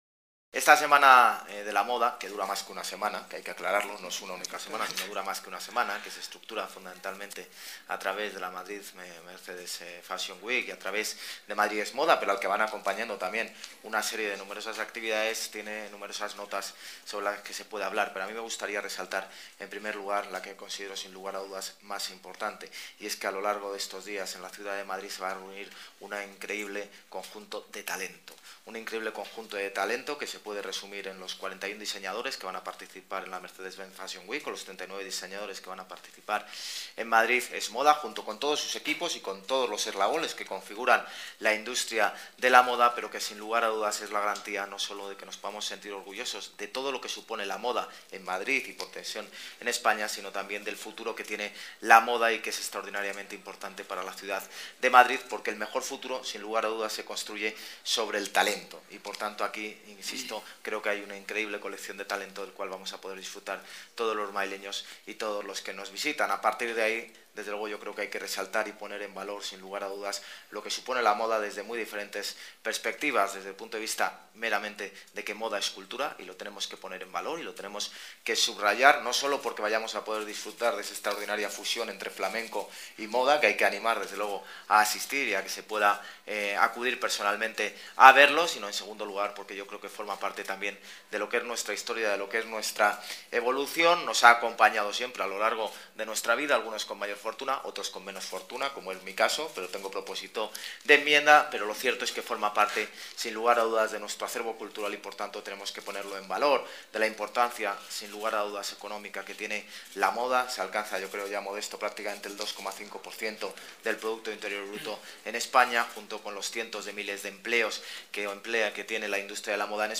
Nueva ventana:Declaraciones del alcalde de Madrid, José Luis Martínez-Almeida